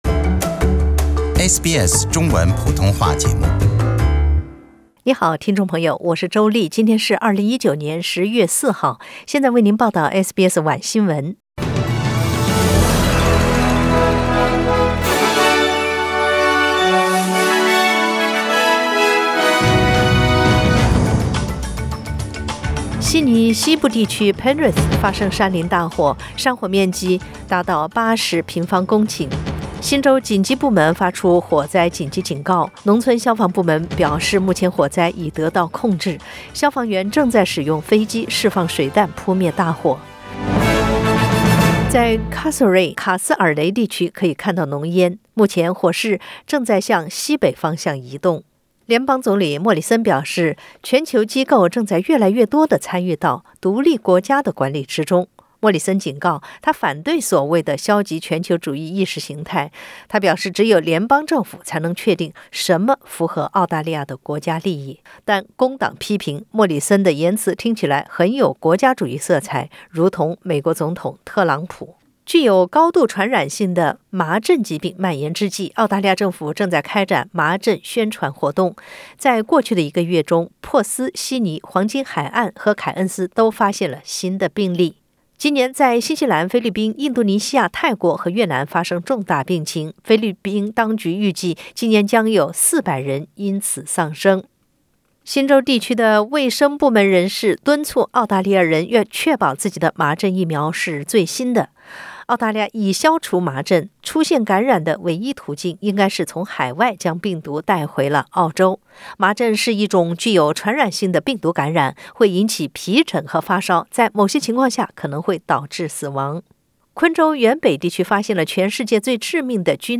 SBS 晚新闻 （10月4日）